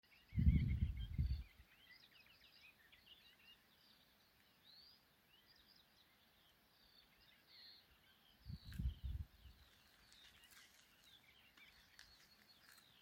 Klusais ķauķis, Iduna caligata
StatussDzied ligzdošanai piemērotā biotopā (D)